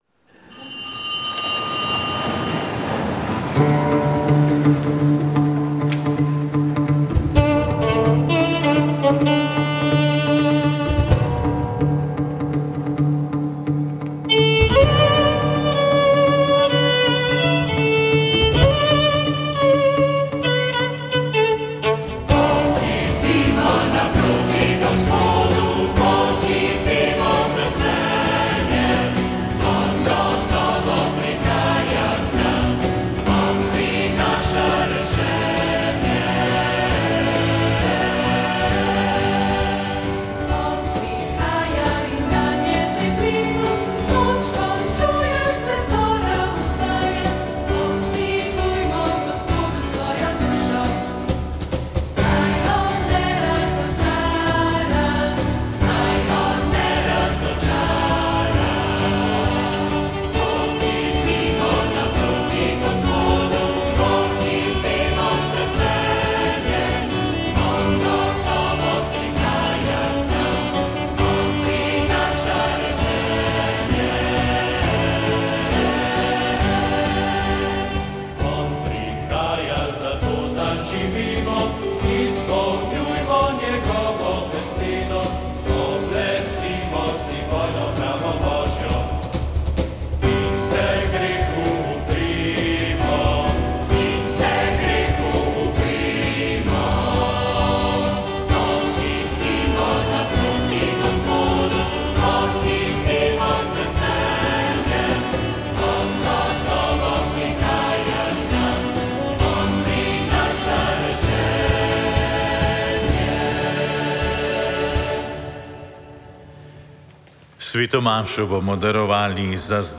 Sv. maša iz stolne cerkve sv. Janeza Krstnika v Mariboru 29. 9.